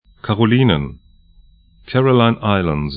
Pronunciation
Karolinen karo'li:nən Caroline Islands 'kærəlaɪn 'aɪləndz